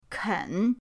ken3.mp3